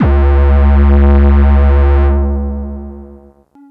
Analog Phat.wav